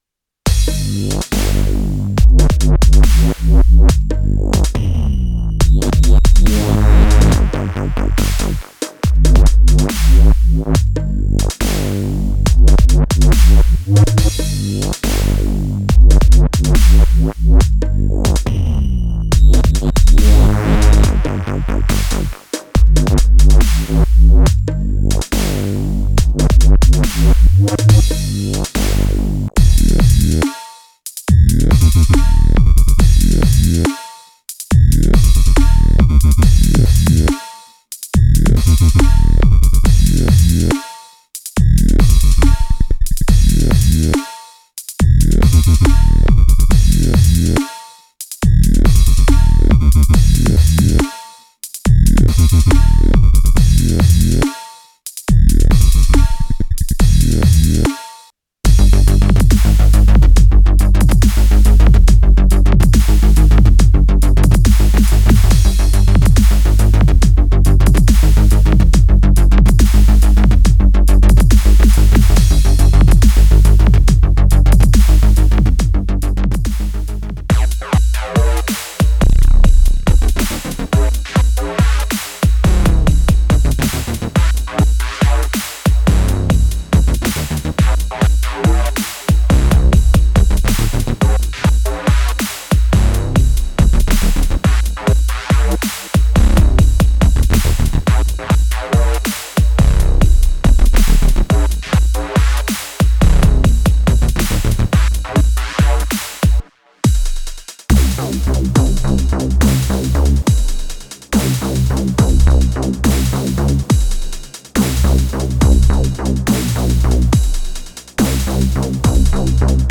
THE DEFINITIVE SOUND PACK FOR DUBSTEP, DRUM & BASS AND JUNGLE LOVERS
A hard-hitting collection of huge wobbles, reeses, 808 subs, heavy deep basses, hoovers, hyper saws, and razor-sharp leads — paired with crafted drum kits.
16 high-quality sets/projects spanning classic Dubstep and its modern evolutions, technical Drum & Bass, Techstep/Neurofunk, and Jungle driven by the legendary breakbeats that define the genre.
BASS-PRESSURE-ABLETON-MOVE-DEMO.mp3